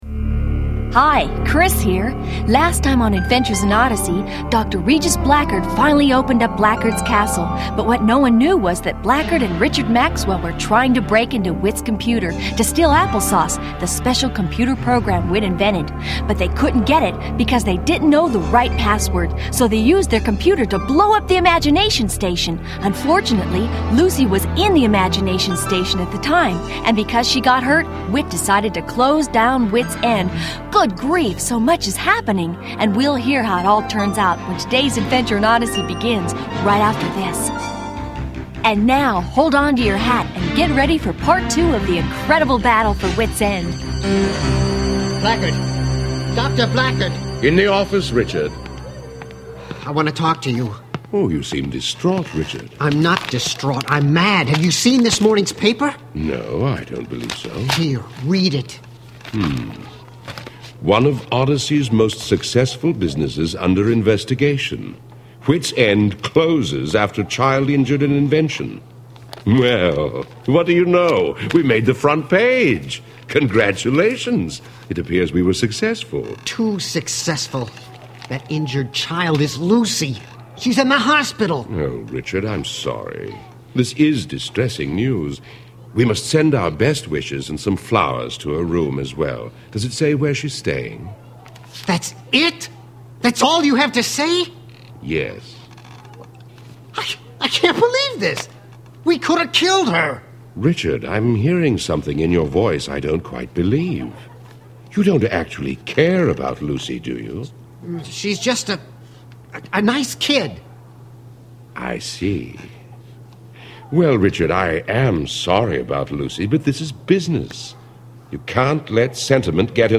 Created for ages 8-12 but enjoyed by the whole family, Adventures in Odyssey presents original audio stories brought to life by actors who make you feel like part of the experience. These fictional, character-building dramas are created by an award-winning team that uses storytelling to teach lasting truths.